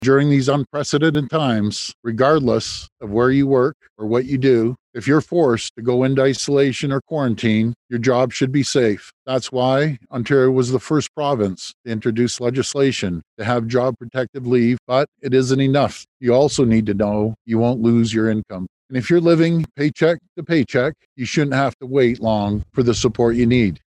Premier Ford even had to pause as he was overcome by emotion when talking about himself being isolated but still being able to work which wasn’t the case for many Ontarians.